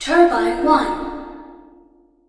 TURBINE1.mp3